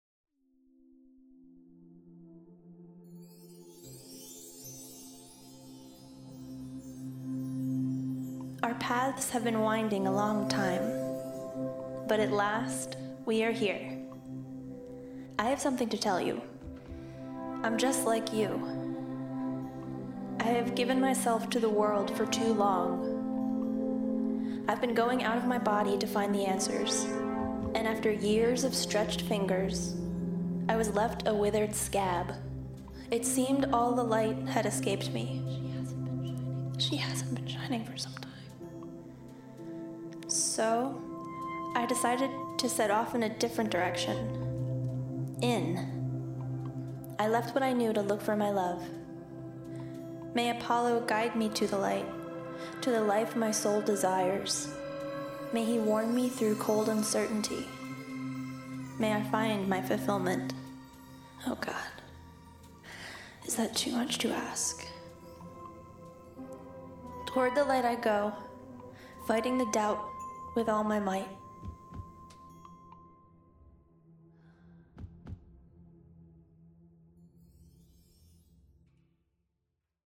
Apollo Fashion Show: a fashion show at Carnegie Mellon University.